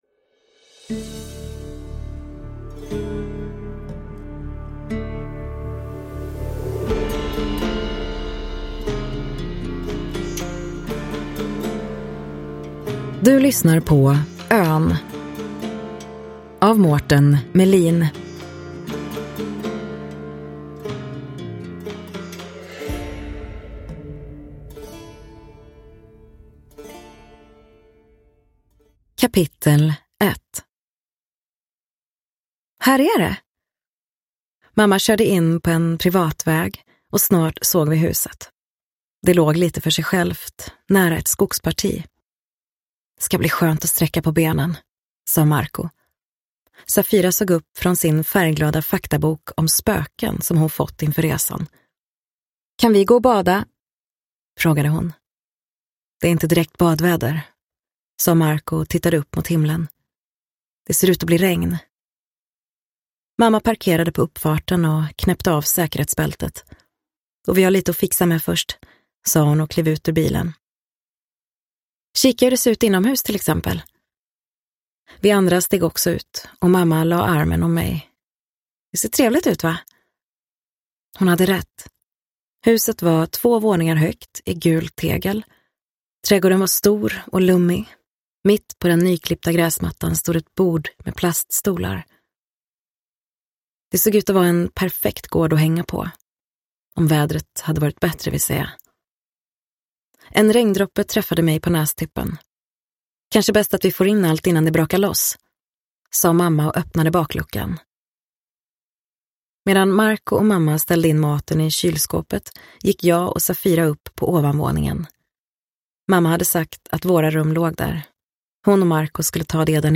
Ön – Ljudbok